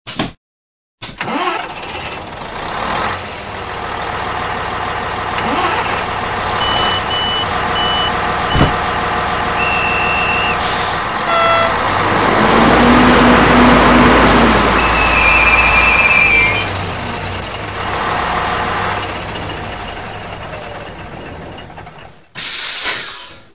Dźwieki do lokomotyw PKP